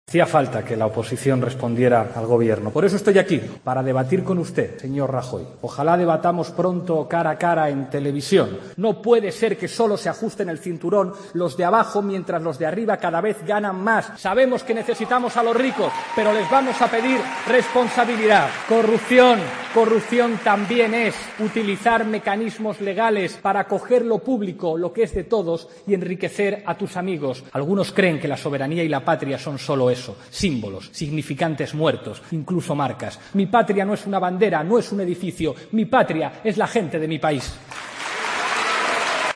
AUDIO: En el acto de Podemos celebrado en el Círculo de Bellas Artes de Madrid para responder a Rajoy por el Debate sobre el Estado de la Nación.